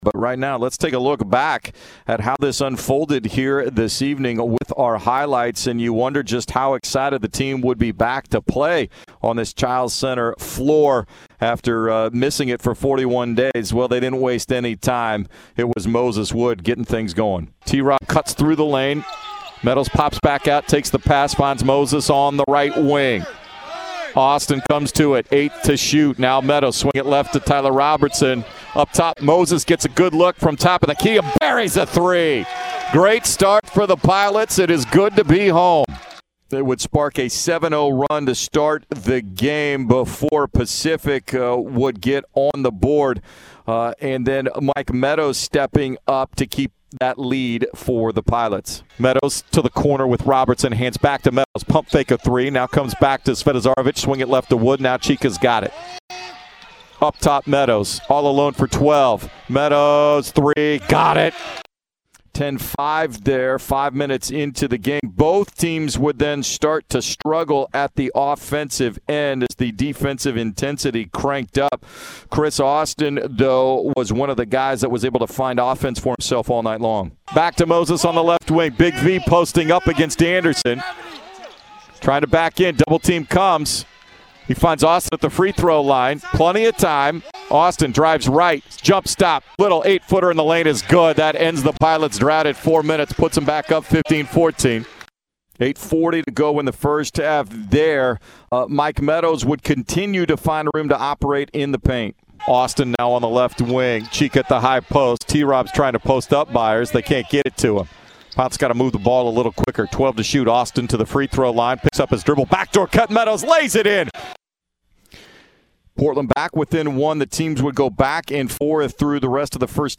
Radio Highlights vs. Pacific